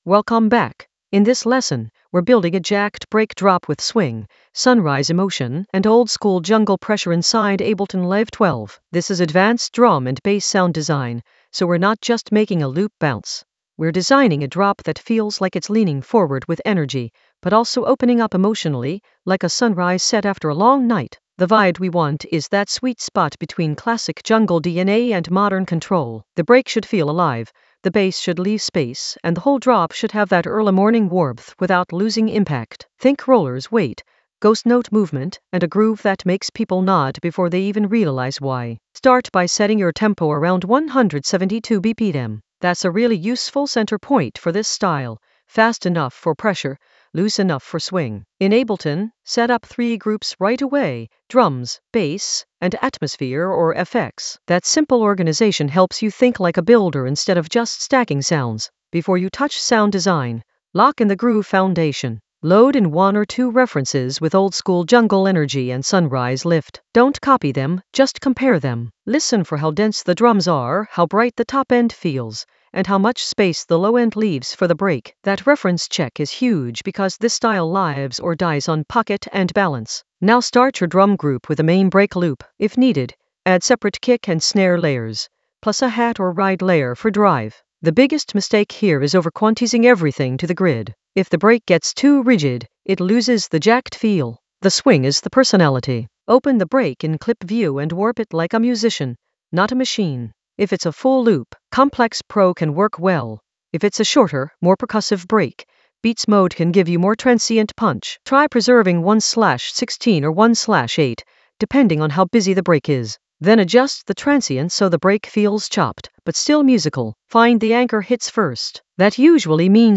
Narrated lesson audio
The voice track includes the tutorial plus extra teacher commentary.
An AI-generated advanced Ableton lesson focused on Jacked Breaks drop swing playbook for sunrise set emotion in Ableton Live 12 for jungle oldskool DnB vibes in the Sound Design area of drum and bass production.